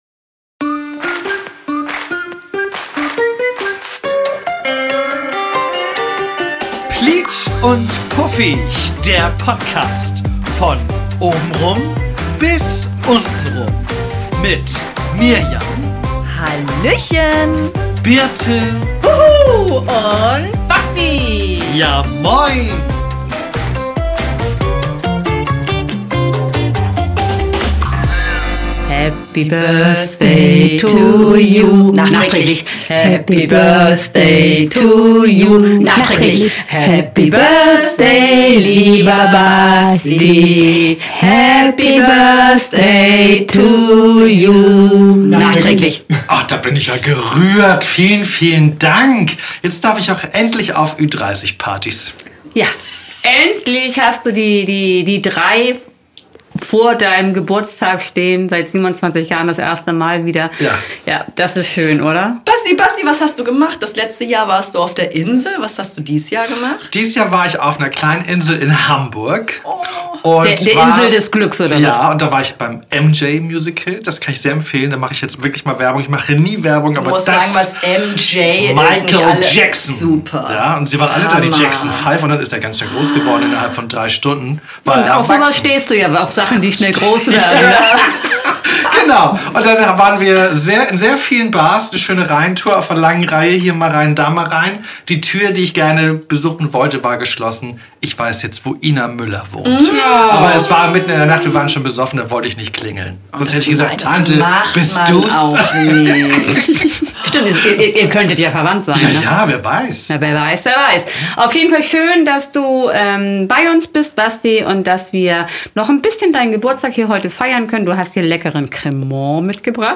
In trauter Runde sind wir erneut eingekehrt in unser heimeliges Podcast Studio und mit vor Freunde hüpfenden Synapsen haben wir uns auf plietsch&puffige Weise den Themen der Stunde hingegeben.